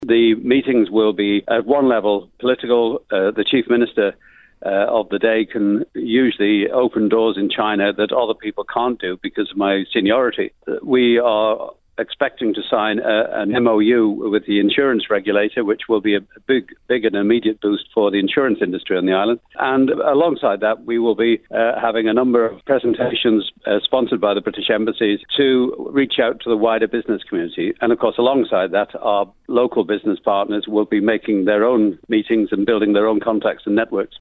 Mr Bell admits there is an element of David and Goliath between the nations but insists the Island only needs to attract a tiny proportion of Chinese business to reap benefits: